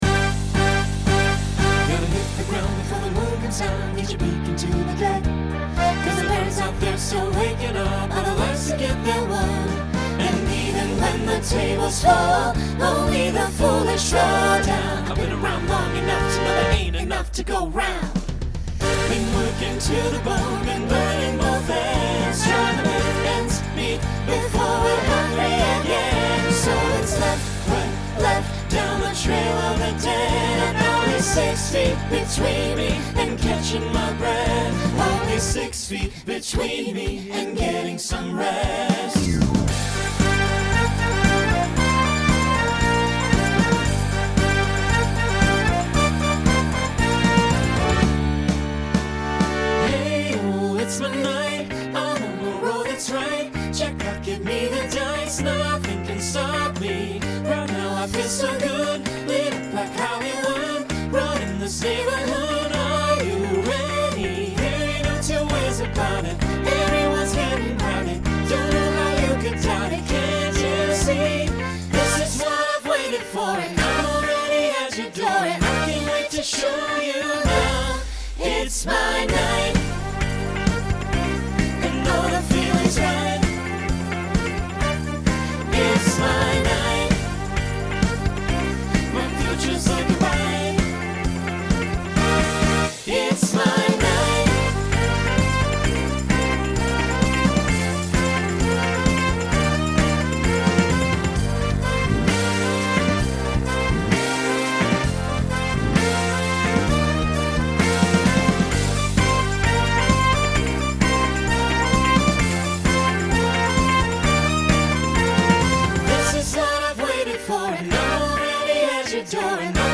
Genre Rock
Story/Theme Voicing SATB